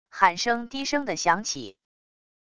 喊声低声的响起wav音频